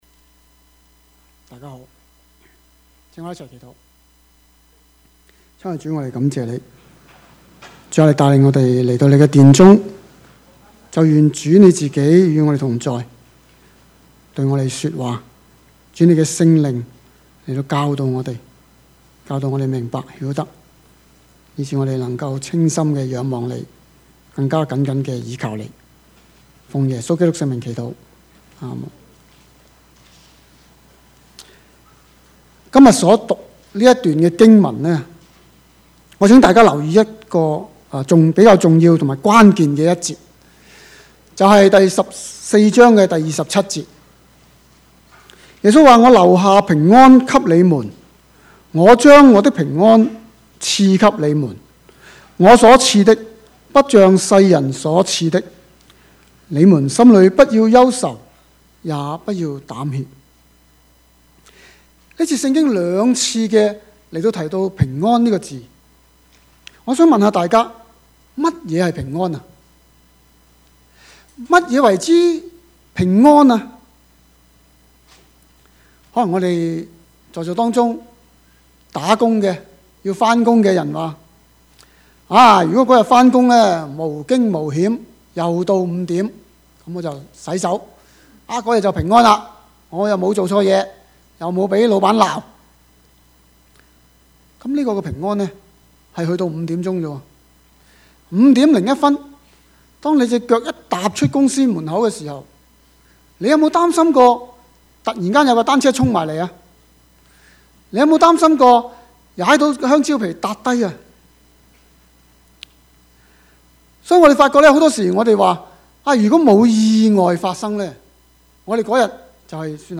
Passage: 約 翰 福 音 14:25-29 Service Type: 主日崇拜
Topics: 主日證道 « 屬靈的爭戰 你不要懼怕！